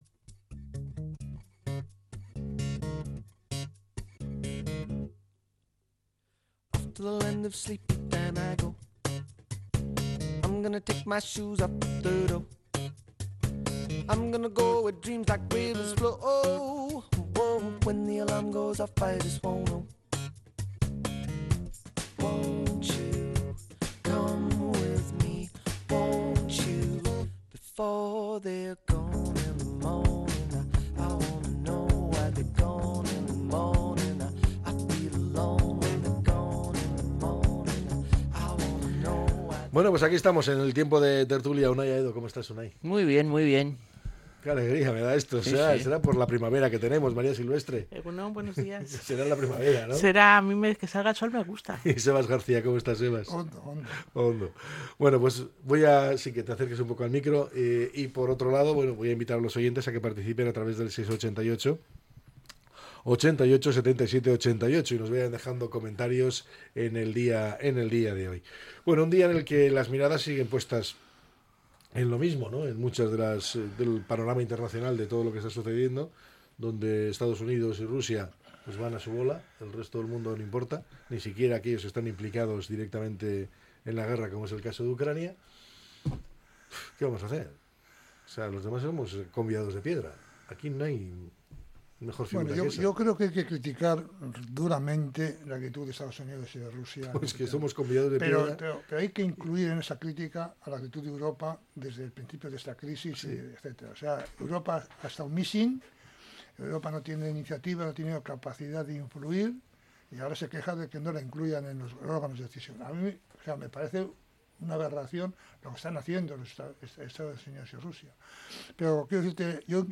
La tertulia 19-02-25.